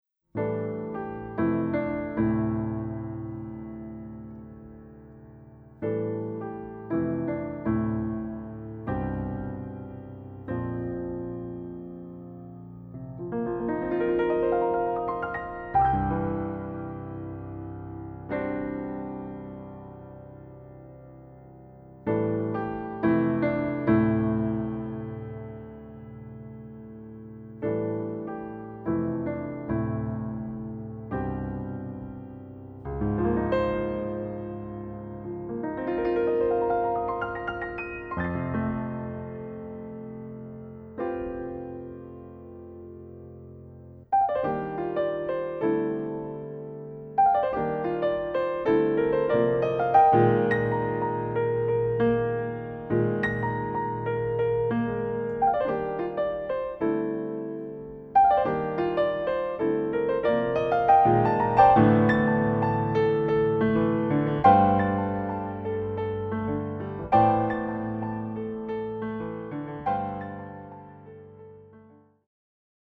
cycle pour piano